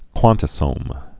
(kwŏntə-sōm)